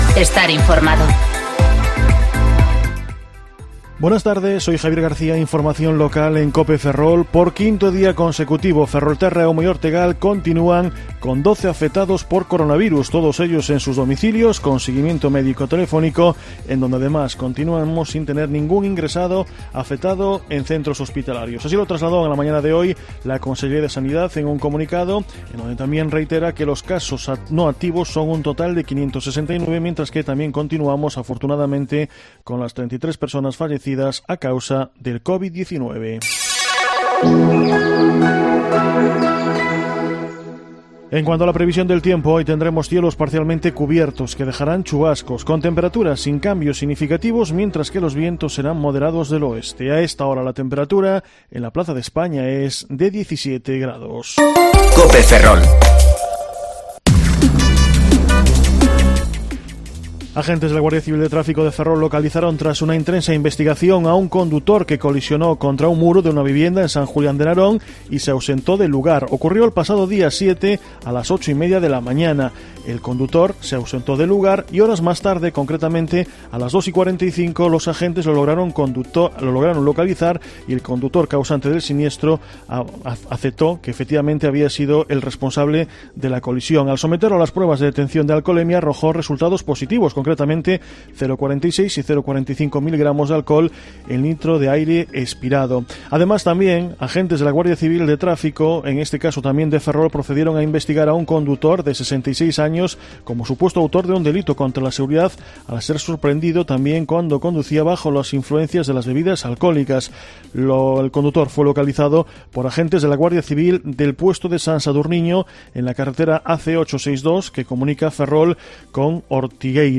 Informativo Mediodía COPE Ferrol 16/06/2020 ( De 14,20 a 14,30 horas)